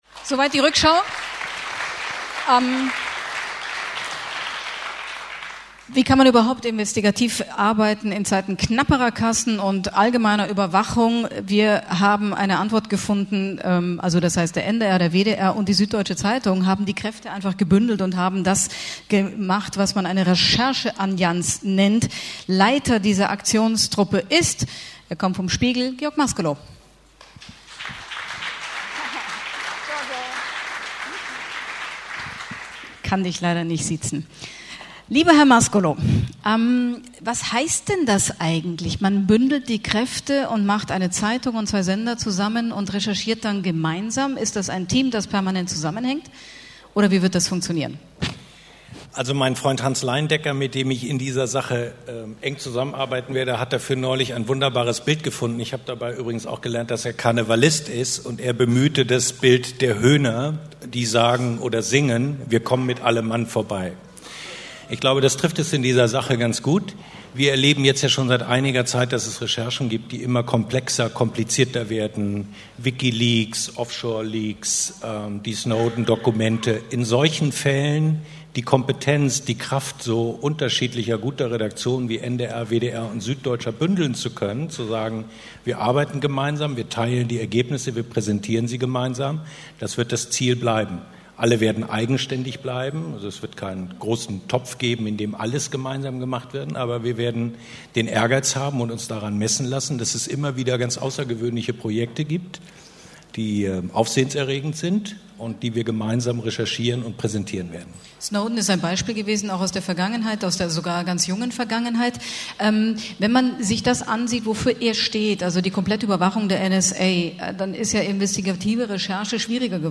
* Sandra Maischberger, Moderatorin
Was: Gespräch über die neue Zusammenarbeit Süddeutsche Zeitung, NDR, WDR
Wo: Berlin, Köthener Straße 38, Meistersaal